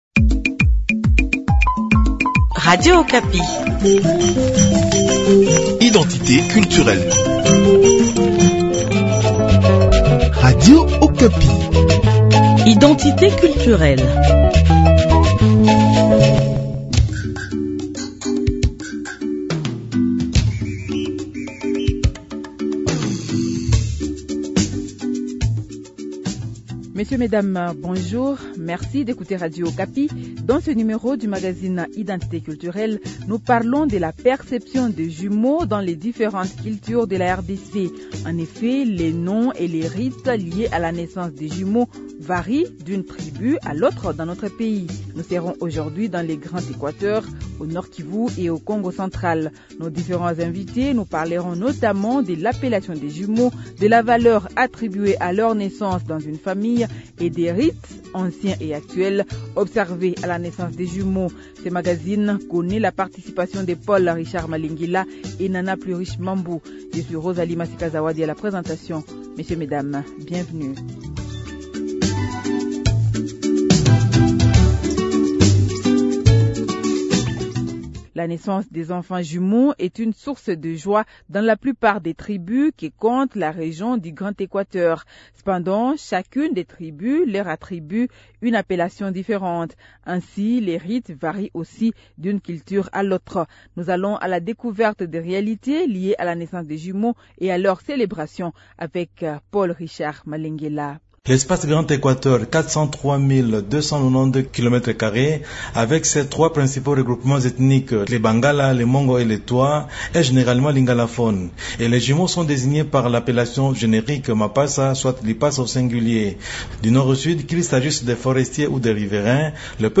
Différents invités expliquant l’appellation des jumeaux, la valeur attribuée à leur naissance dans une famille, et des rites, anciens et actuels, observés à la naissance des jumeaux.